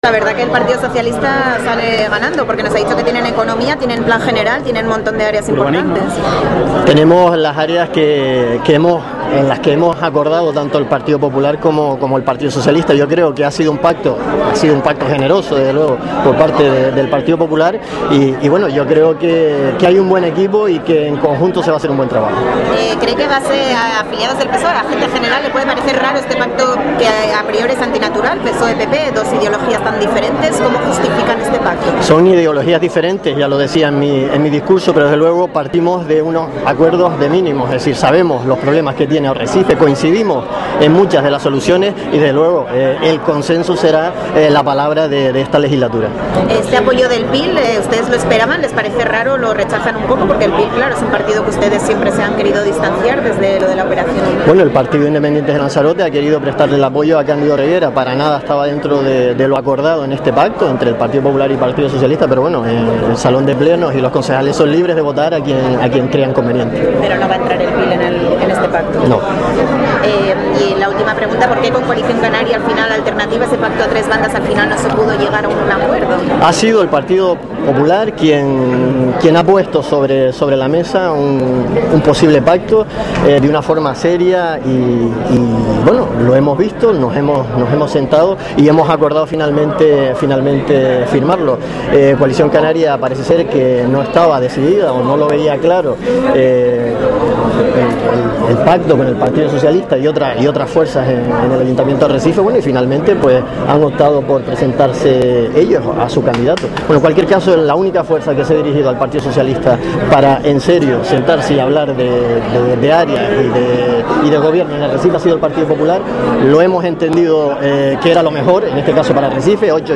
Escuche aquí las declaraciones de Reguera, Montelongo, Fajardo Feo y Espino tras el pleno de Arrecife